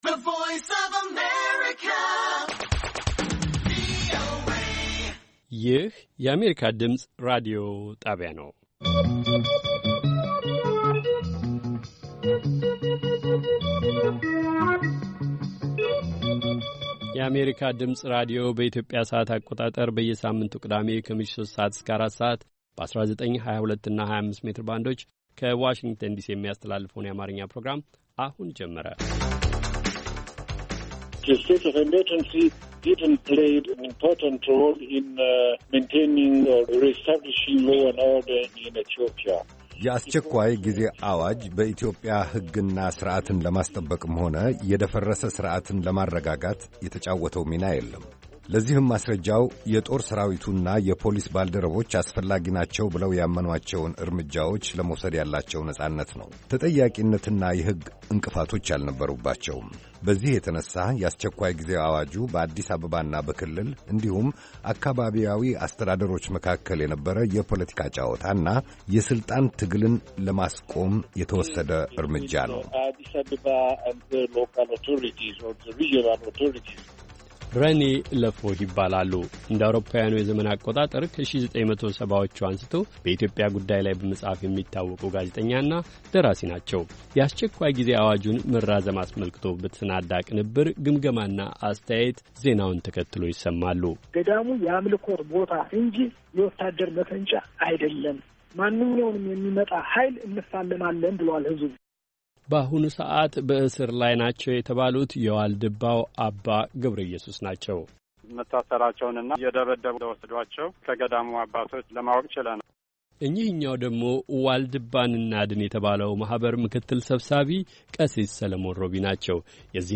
ቅዳሜ፡-ከምሽቱ ሦስት ሰዓት የአማርኛ ዜና